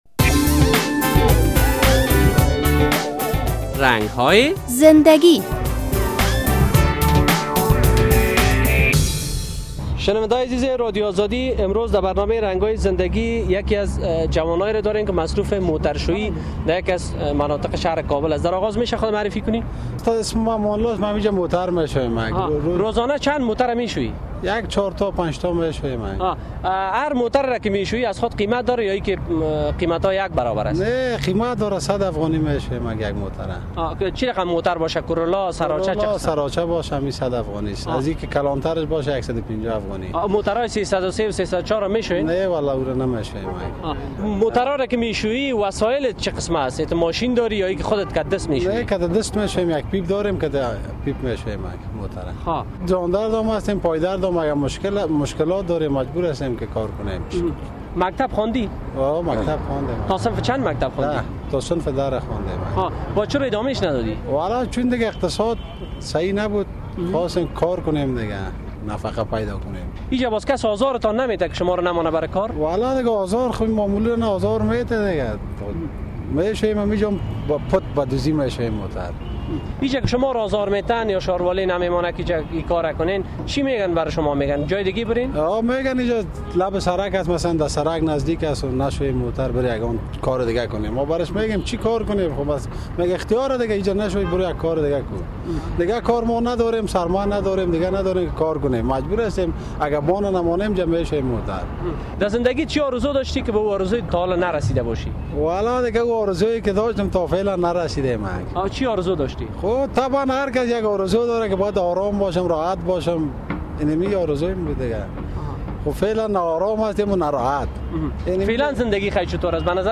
در این برنامهء رنگ های زندگی با جوانی که مصروف موتر شویی در کابل است صحبت شده است. با کلیک روی لینک زیر می توانید این مصاحبه را بشنوید...